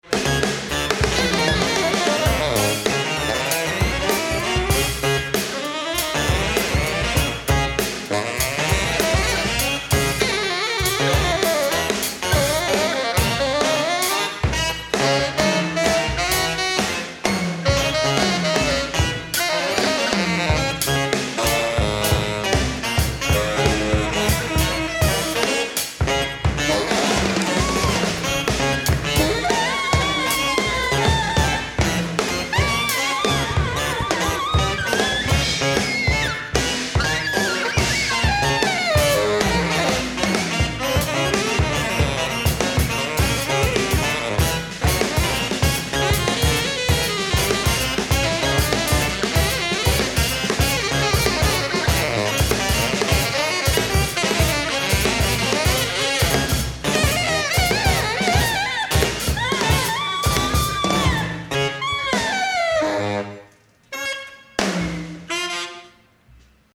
Recorded live at the 39th Street loft in Brooklyn
drums
alto saxophone, fx
tenor saxophone
Stereo (Metric Halo / Pro Tools)